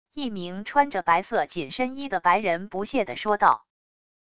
一名穿着白色紧身衣的白人不屑的说道wav音频